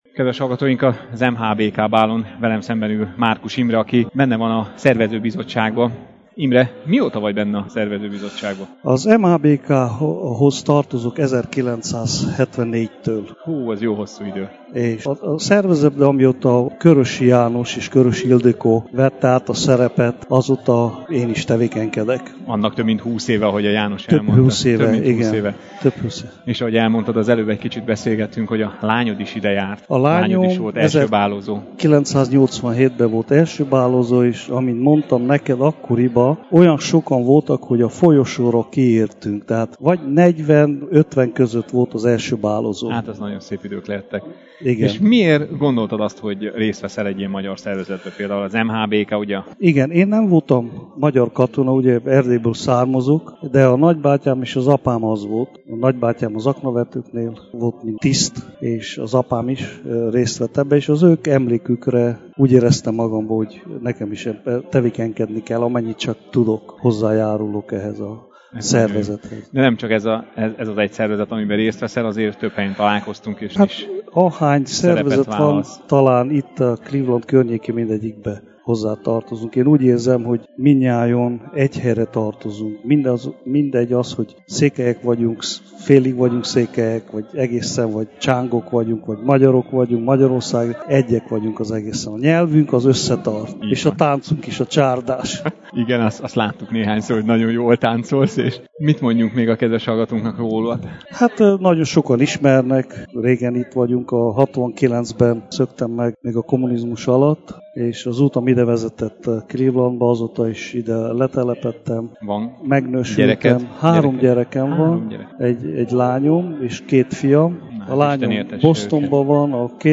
Beszámoló a 2014-es clevelandi MHBK bálról – Bocskai Rádió